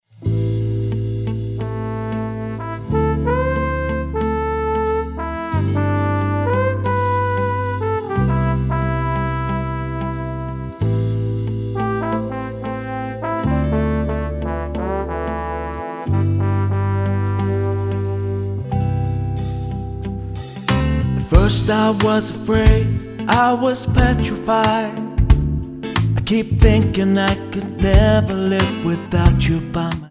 --> MP3 Demo abspielen...
Tonart:Am Multifile (kein Sofortdownload.